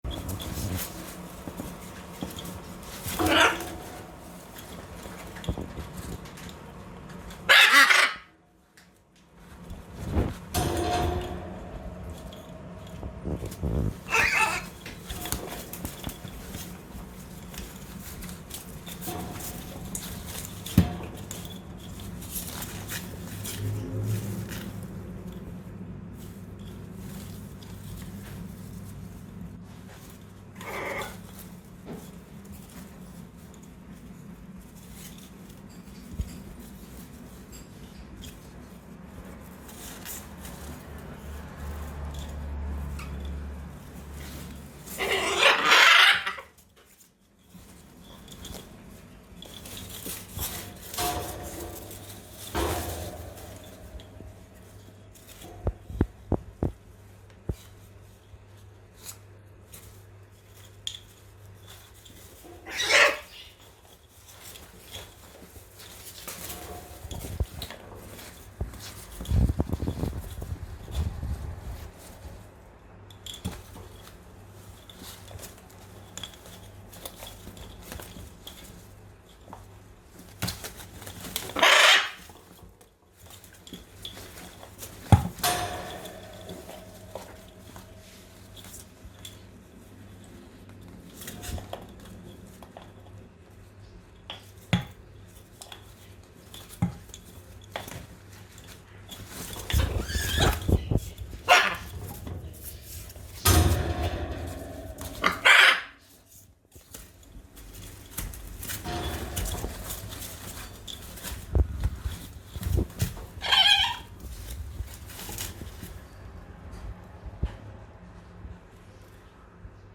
sine-zeltyi-ara-baluetsya.mp3